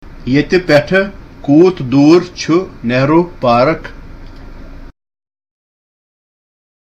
A CONVERSATION WITH A TONGA DRIVER